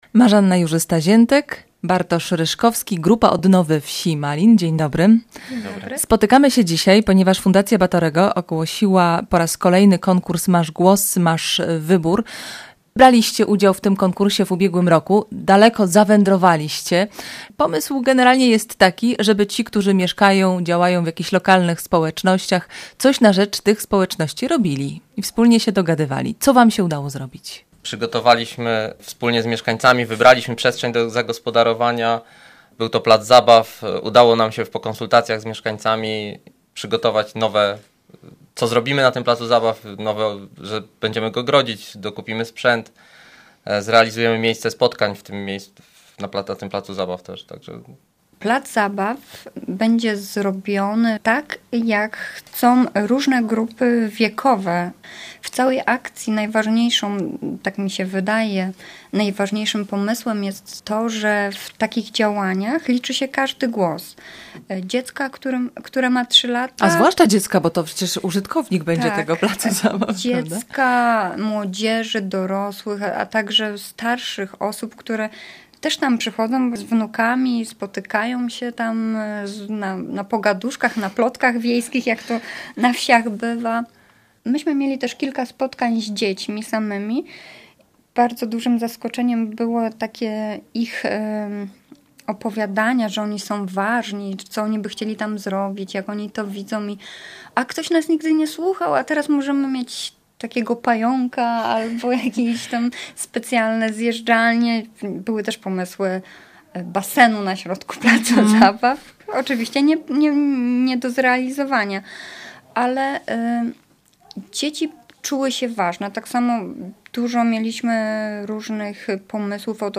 Rozmowa w Radio Wrocław